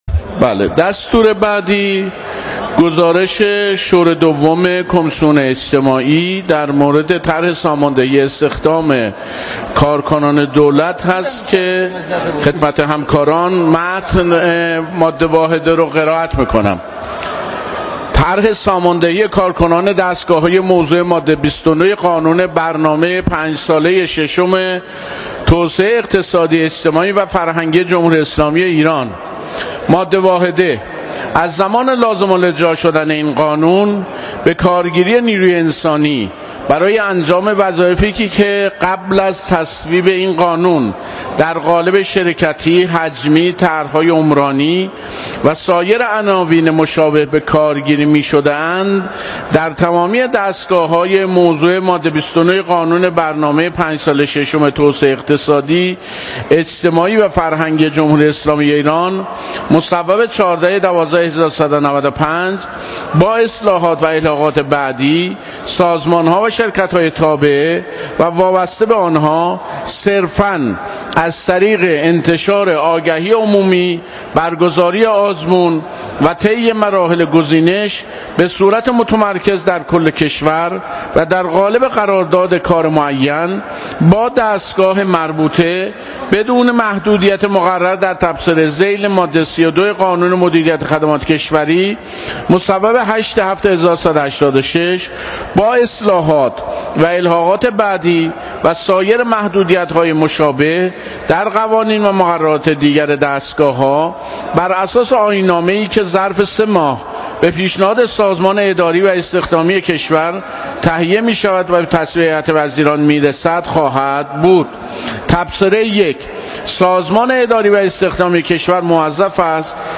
مشروح مذاکرات نمایندگان در جلسه تصویب طرح ساماندهی کارکنان دولت: